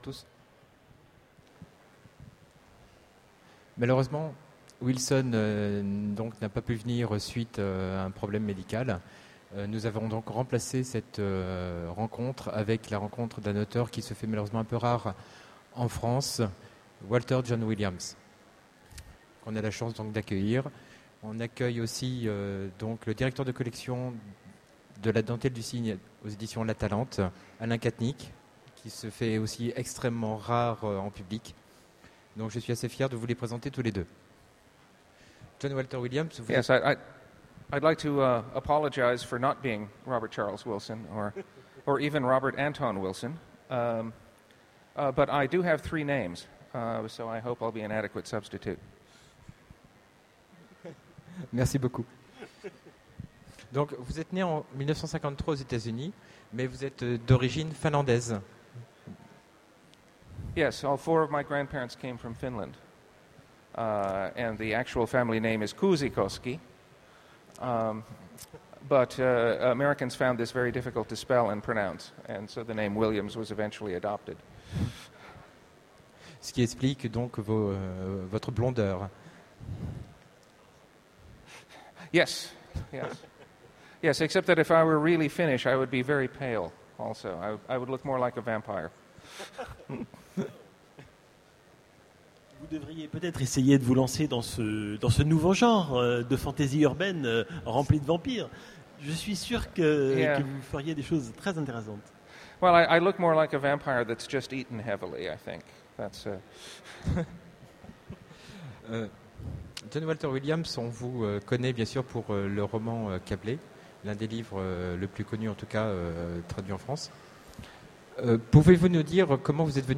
Utopiales : Conférence-Rencontre avec Walter Jon Williams
Voici l'enregistrement de la rencontre avec Walter Jon Williams aux Utopiales 2009.
Télécharger le MP3 à lire aussi Walter Jon Williams Genres / Mots-clés Rencontre avec un auteur Conférence Partager cet article